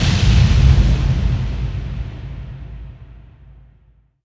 VEC3 FX Reverbkicks 06.wav